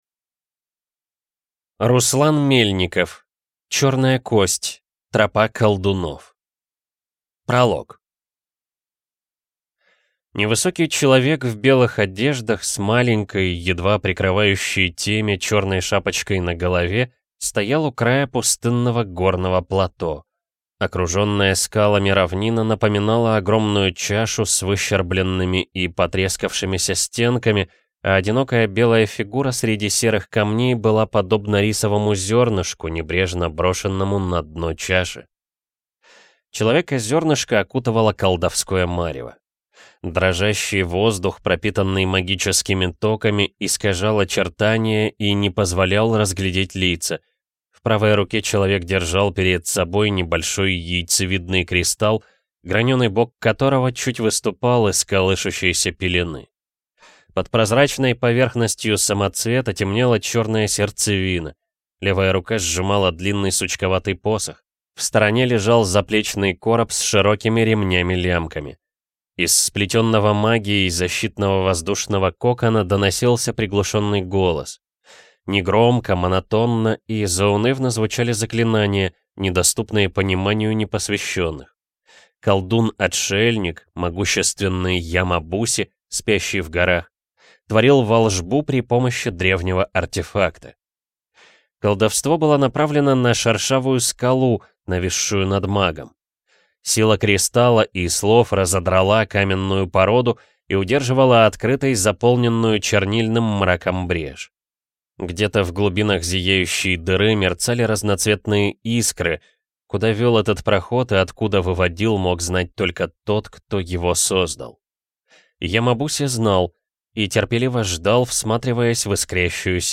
Аудиокнига Тропа колдунов | Библиотека аудиокниг